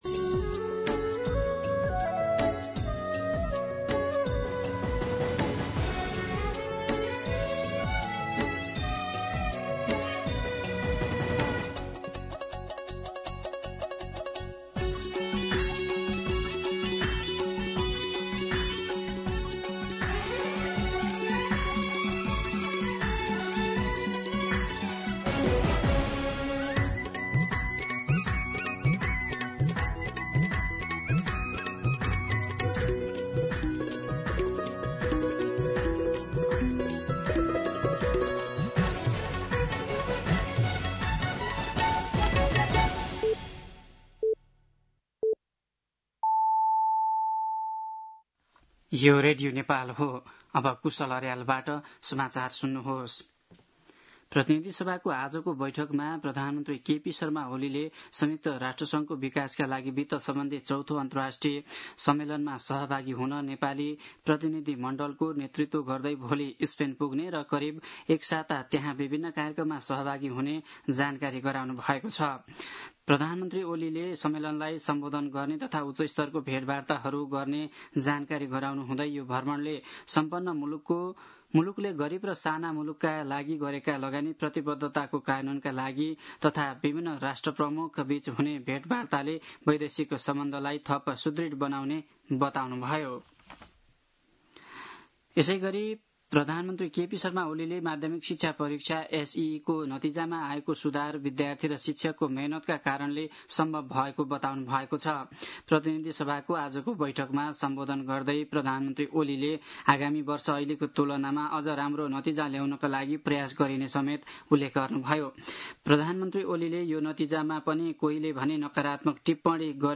दिउँसो ४ बजेको नेपाली समाचार : १४ असार , २०८२
4-pm-Nepali-News-3.mp3